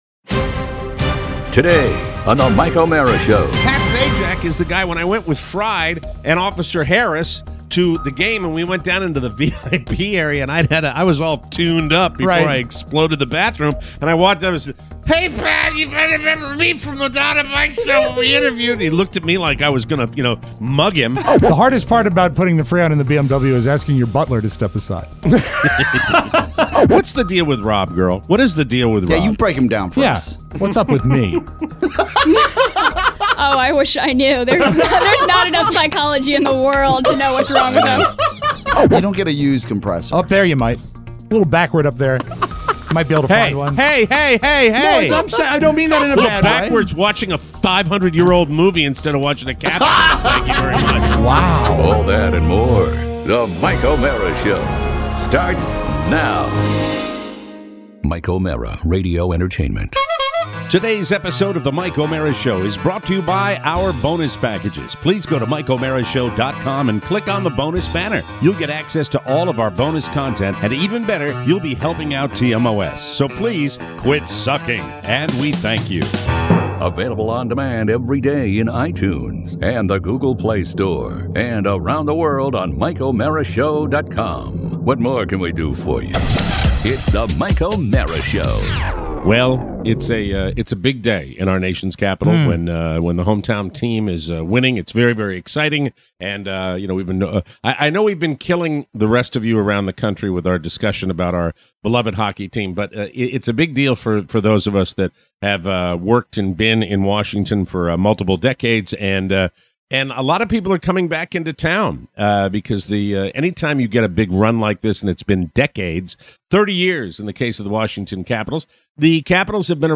Today, an in studio appearance from an old friend.